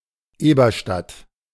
Eberstadt (German: [ˈeːbɐʃtat]